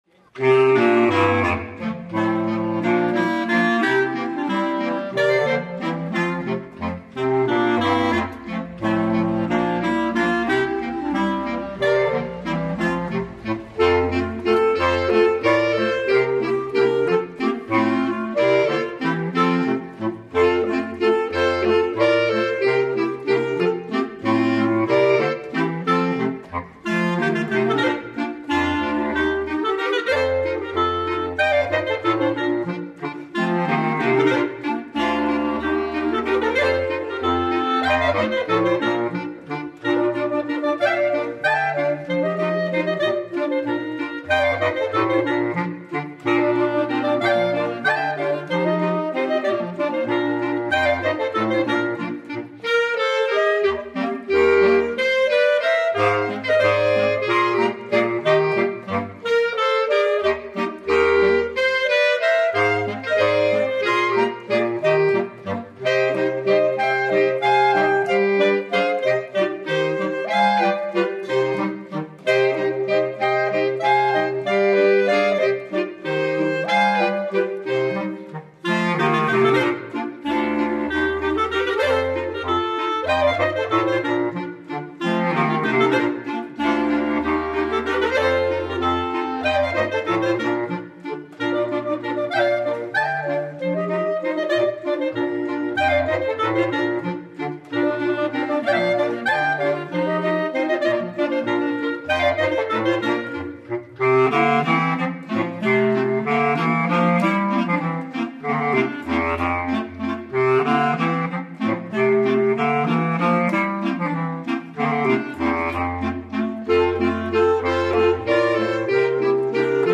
BR-Aufnahme 2025 im Freilandmuseum Neusath
Zwiefacher "I bin da Wirt vo Stoa" - Dauer: 2:33